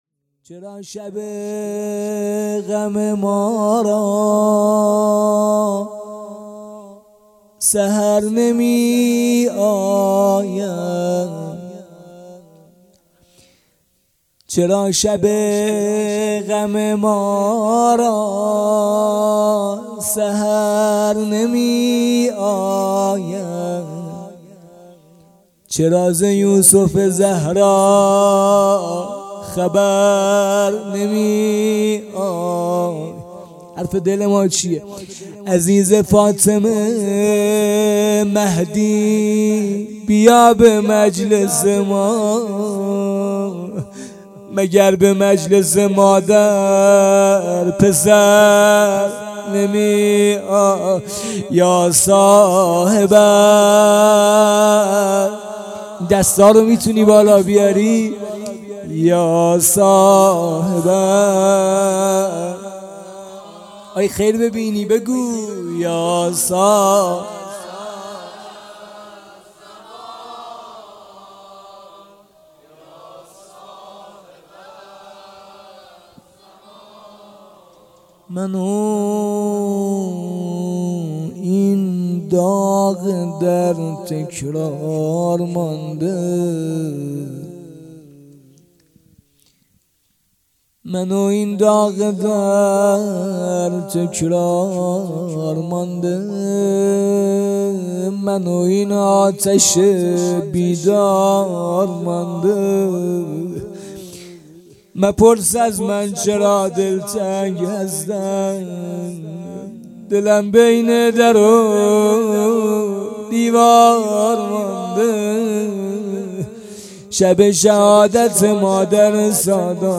هيأت یاس علقمه سلام الله علیها
اقامه عزای شهادت حضرت زهرا سلام الله علیها _ دهه دوم فاطمیه _ شب دوم